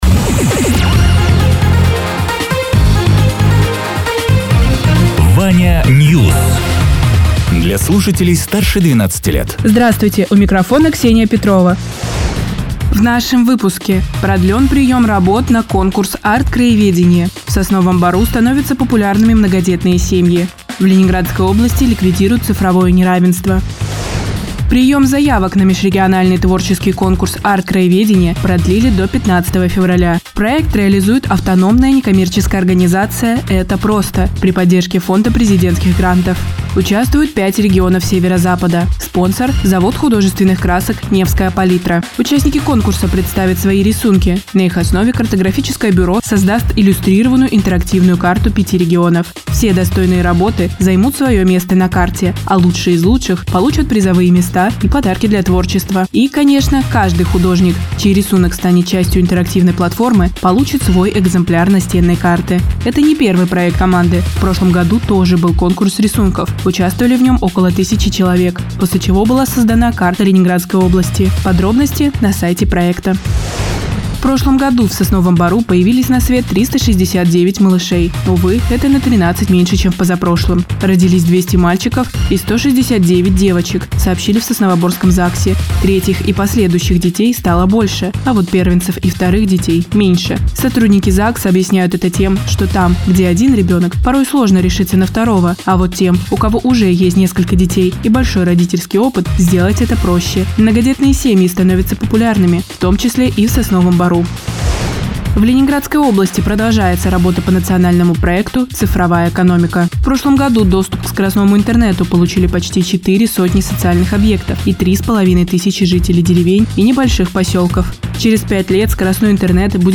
Радио ТЕРА 23.01.2025_08.00_Новости_Соснового_Бора